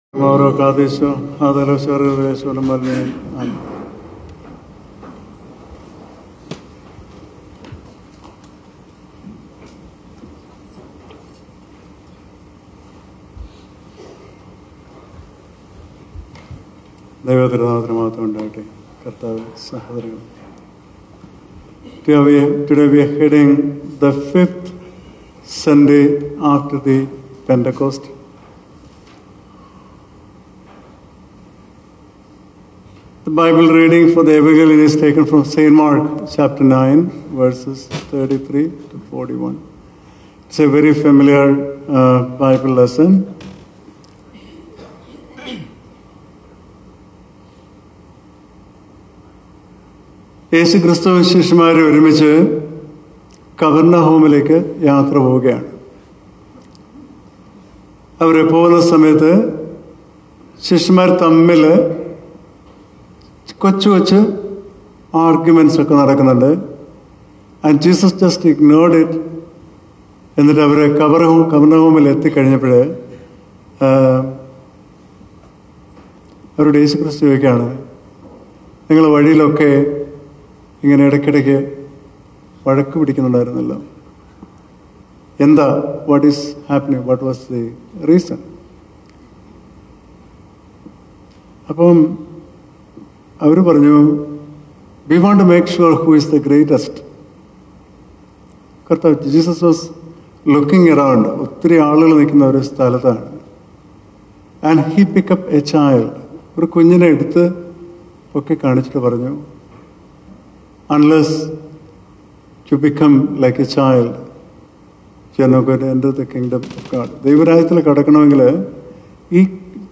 Sermon – July 13-2025 – St. Mark 9: 33-41